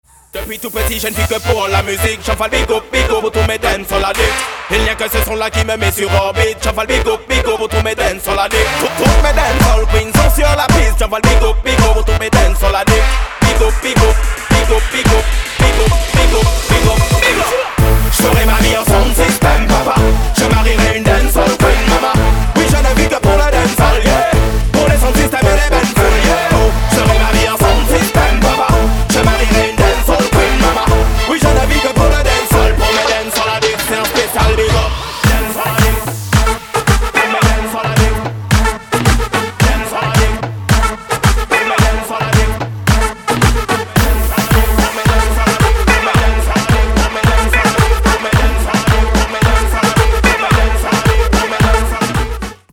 • Качество: 256, Stereo
ритмичные
мужской вокал
громкие
EDM
электронная музыка